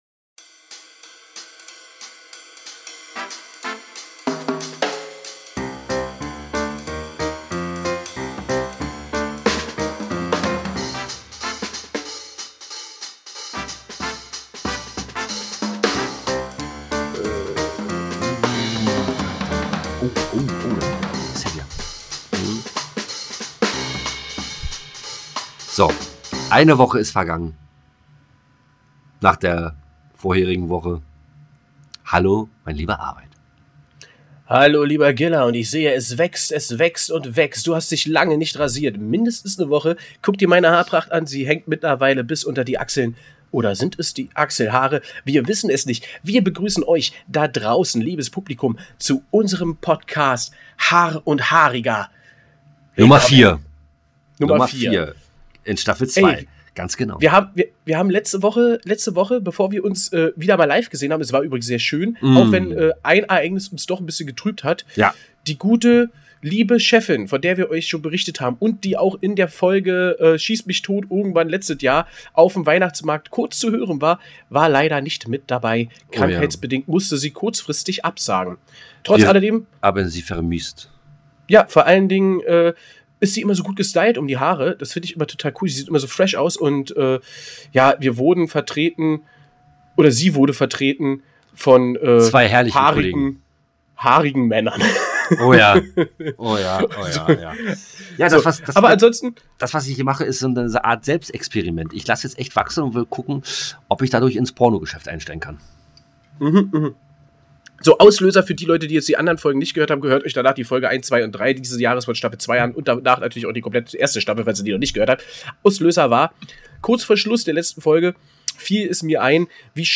Jetzt auch als Hörspiel!